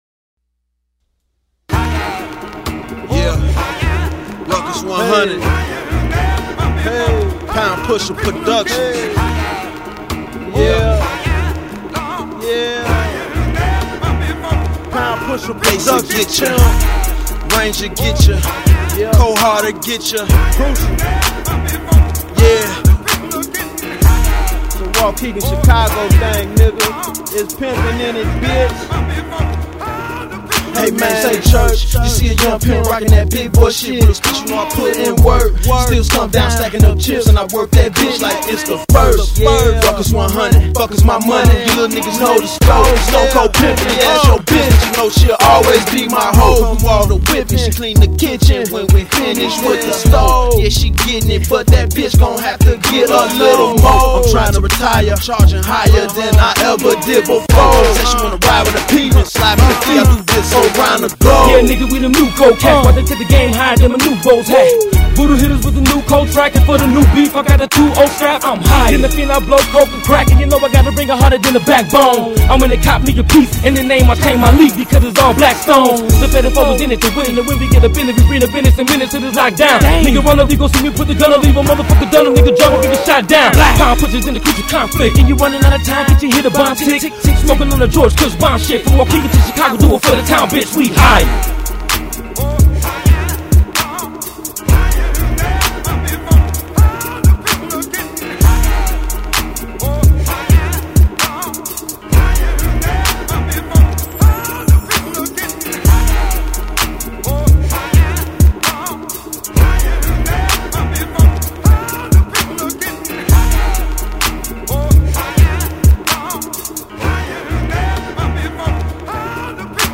So soulful.